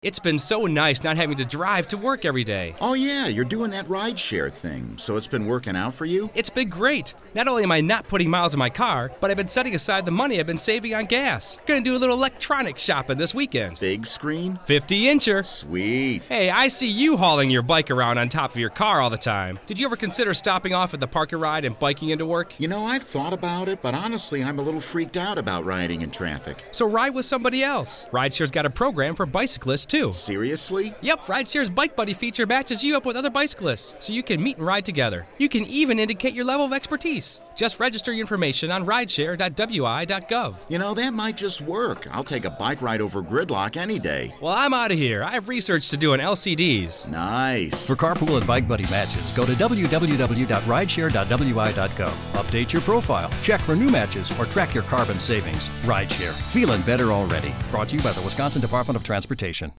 Bicyclists can register as either an experienced rider or novice Radio ad - Bike buddy feature - Man encourages friend to try riding to work with a bike buddy.